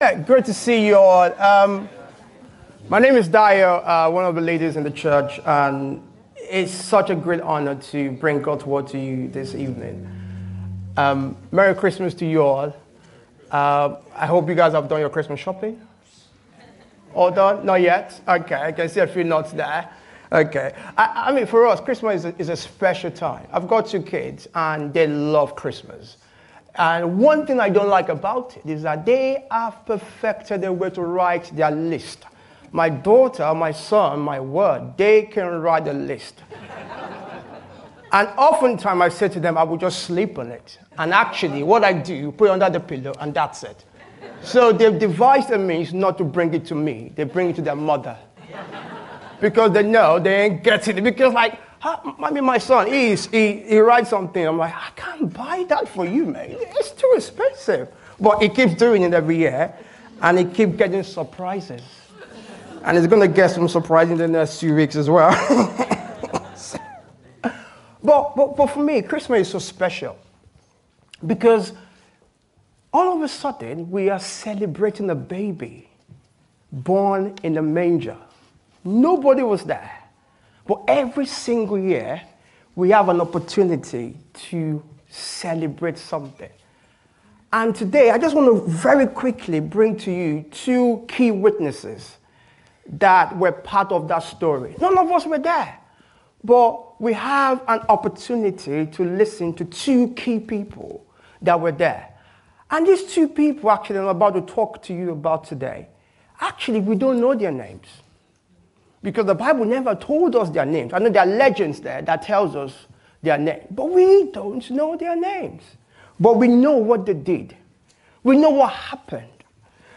Pondering Treasure | Carol Service
Carol+Service+2024.mp3